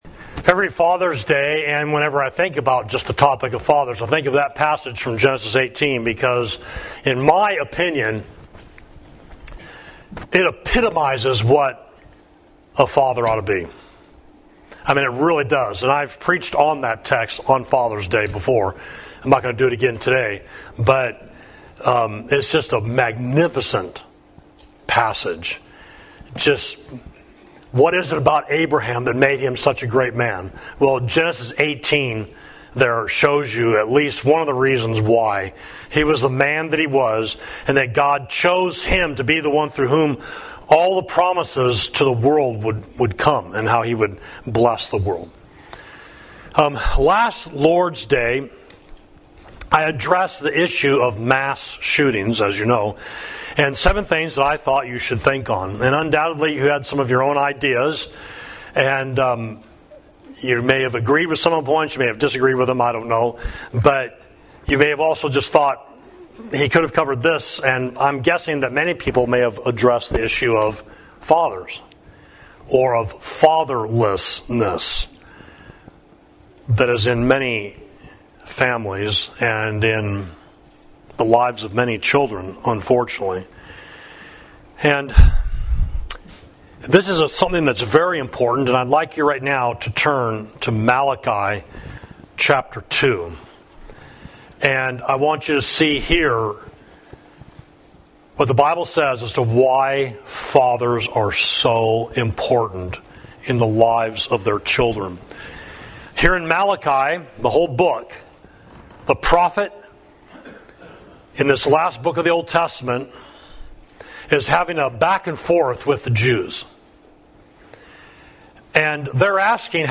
Sermon: The Blessed Father, Psalm 112